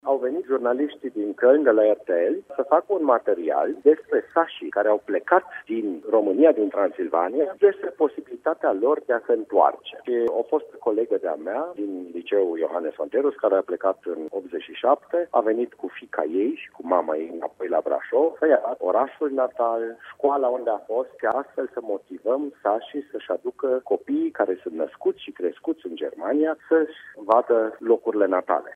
Direct din Germania, consilierul local Cristian Macedonschi, ne-a oferit mai multe detalii: